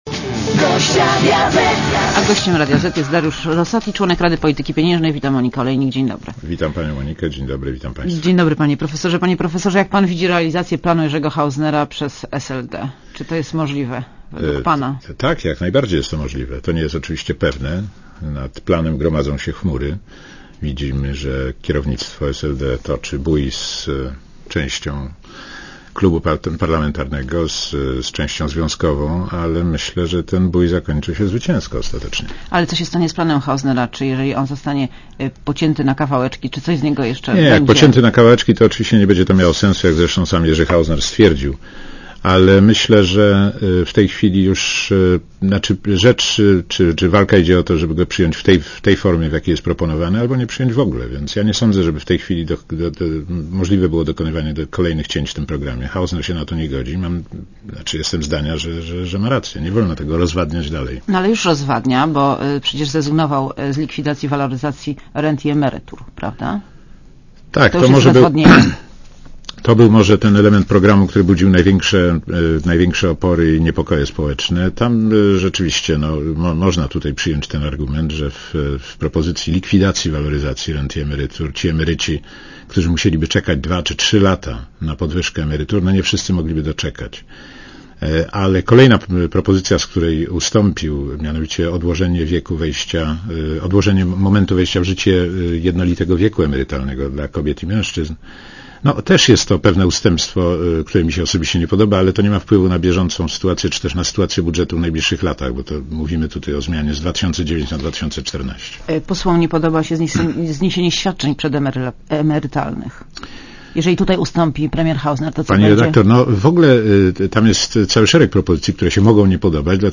Dariusz Rosati w Radiu Zet (RadioZet)